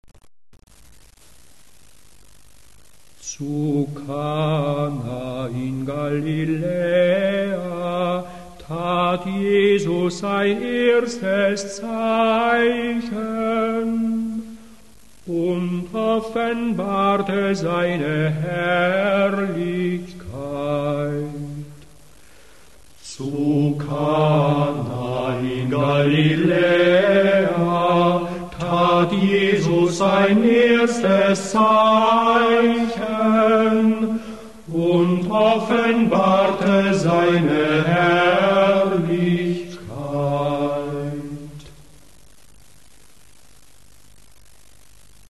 2. Vesper
Magnificat - Antiphon   Ich habe gesehen und bezeuge: Dieser ist Gottes Sohn.